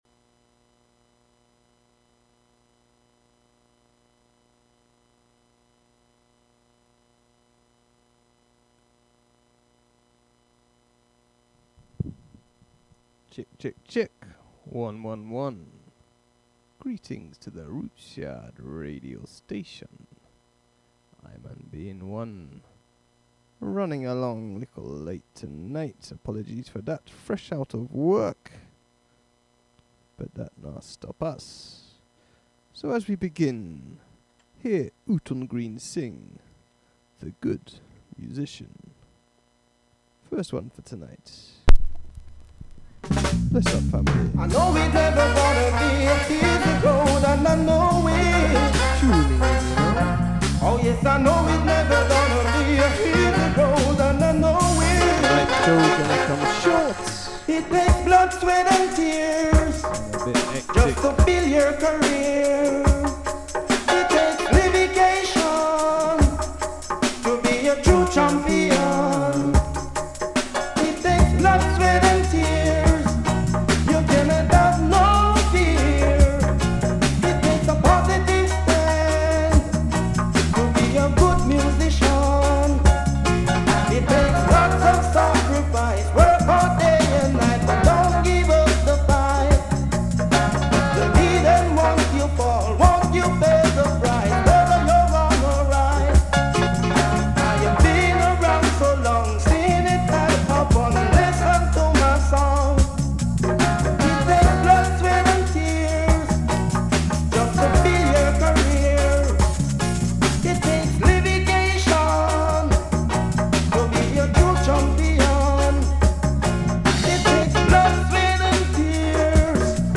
Bass Jam Session